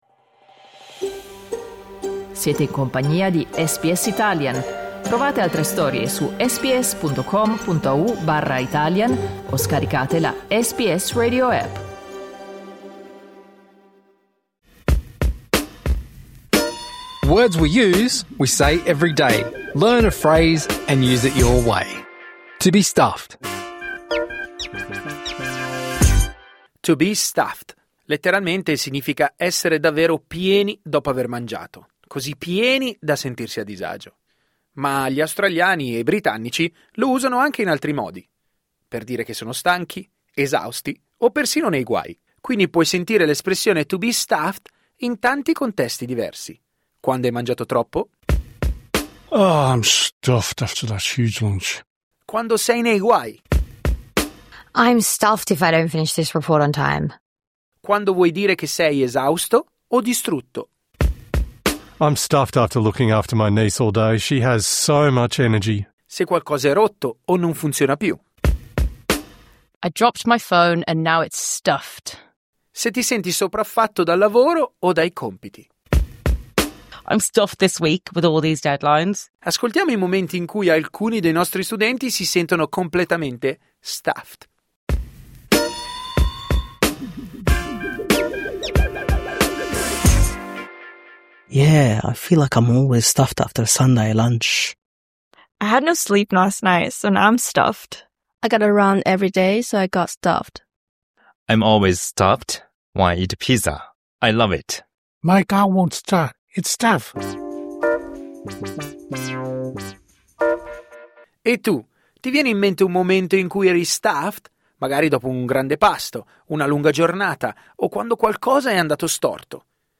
Words We Use è una serie bilingue che ti aiuta a comprendere espressioni idiomatiche come "To be stuffed".